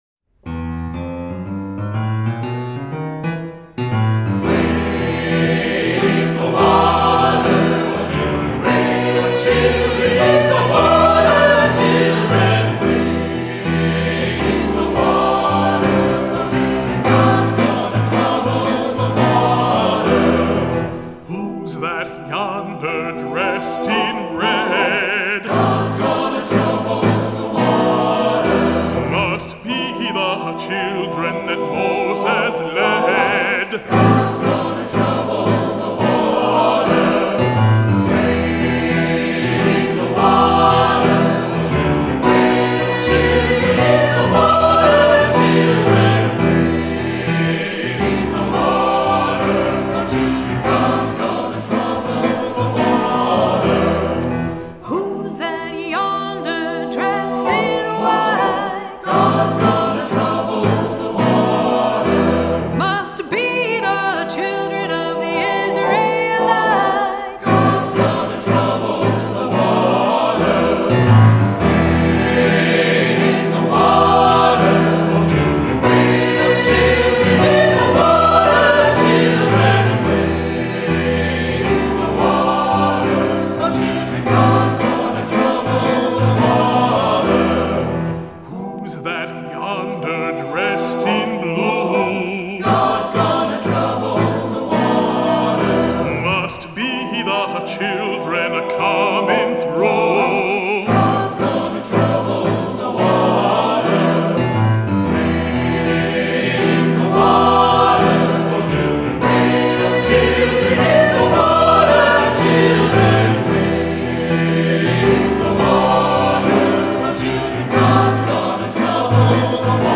Voicing: SATB; Soloist or Soloists